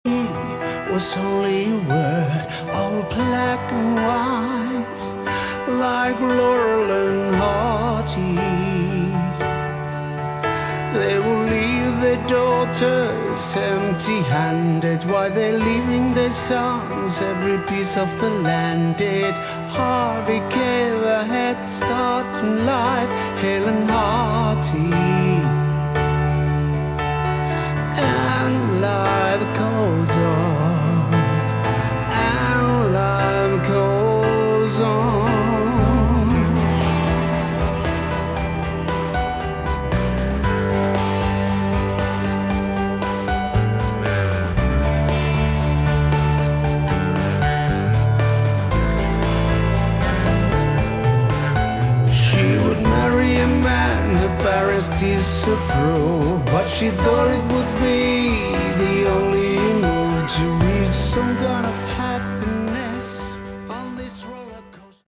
Category: Hard Rock
vocals
guitars, keyboards, programming
organ
bass
drums